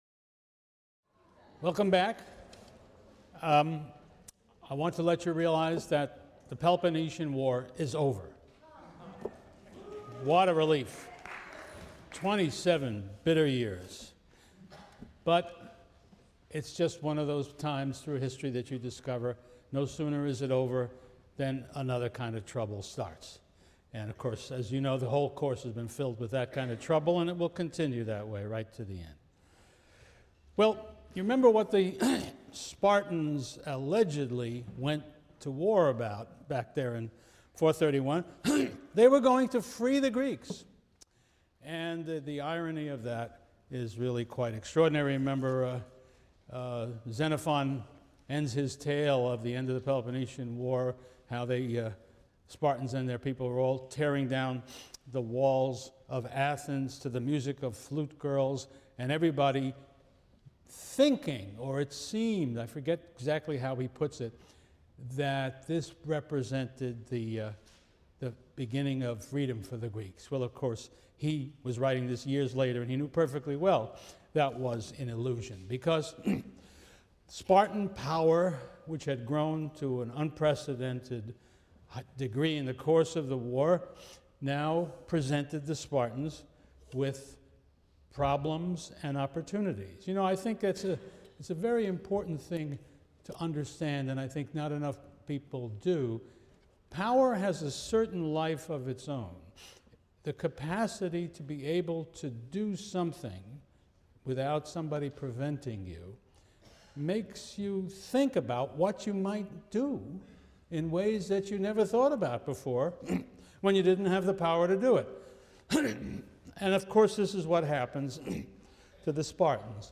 CLCV 205 - Lecture 21 - The Struggle for Hegemony in Fourth-Century Greece | Open Yale Courses